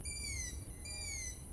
premessa sul Gufo comune e poi il canto dei giovani gufi!
In questo periodo i giovani gufi sono davvero tra gli uccelli più vociferi, solo che non immaginatevi canti lugubri o inquietanti...la voce dei giovani gufi è molto acuta...e per questo vi allego un file per ascoltare il canto di giovani gufi comuni che hanno nidificato in un parco fluviale non lontano da casa.
spero vi piacciano...sono due "fratelli" giovani gufi comuni!
sapete anche della schiusa asincrona delel uova, il primo Gufo che canta era più vecchio di qualche giorno...rispetto al secondo! ma questa è solo una curiosità!